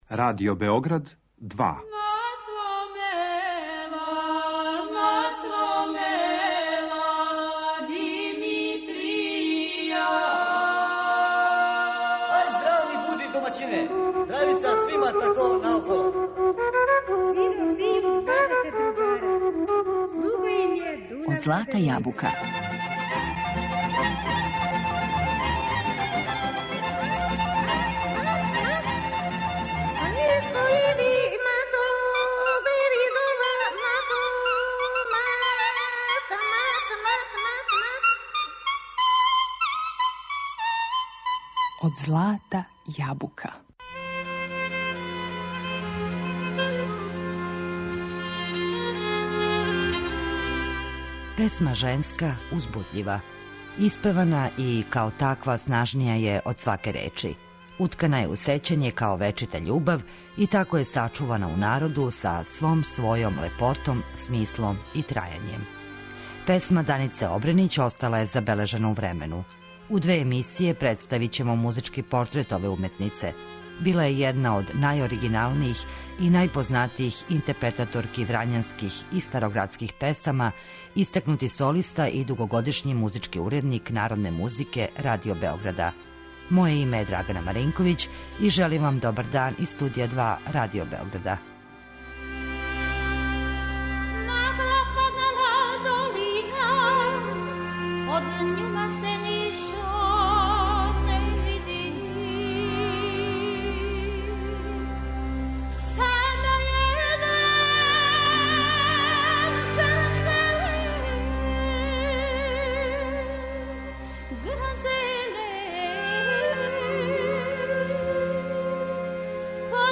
Била је једна од најоригиналнијих и најпознатијих интерпретатора врањанских и староградских песама.